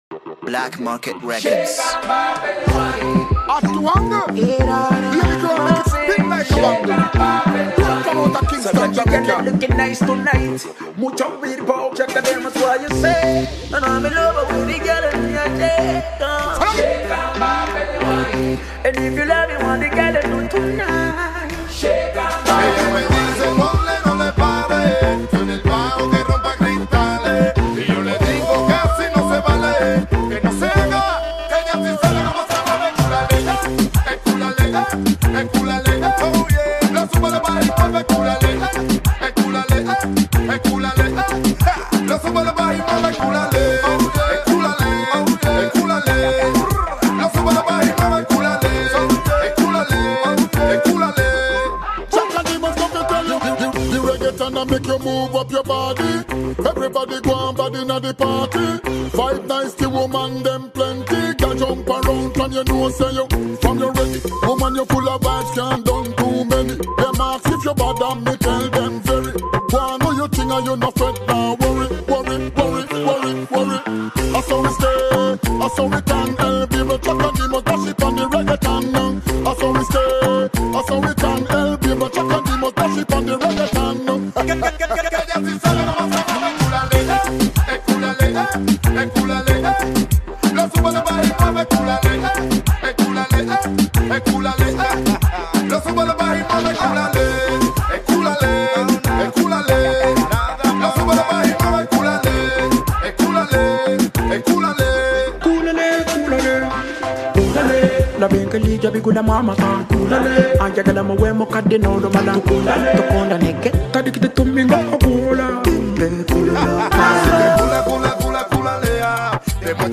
Afro Beat singer